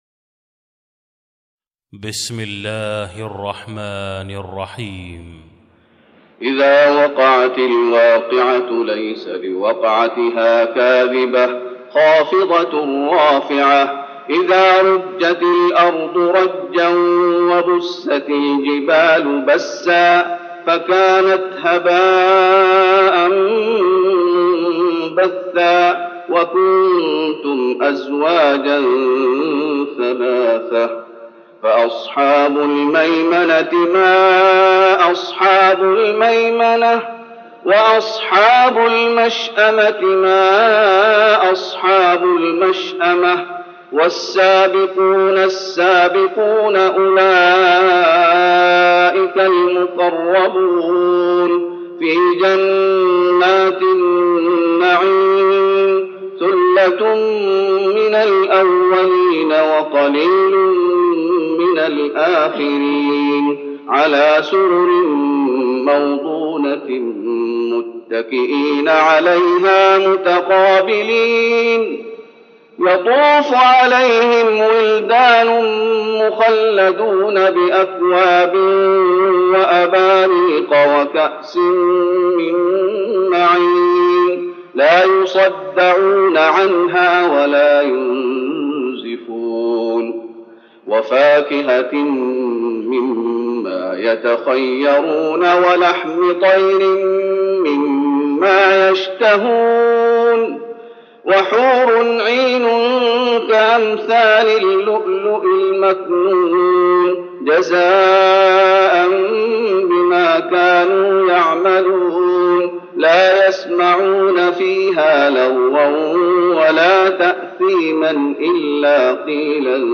تراويح رمضان 1413هـ من سورة الواقعة Taraweeh Ramadan 1413H from Surah Al-Waaqia > تراويح الشيخ محمد أيوب بالنبوي 1413 🕌 > التراويح - تلاوات الحرمين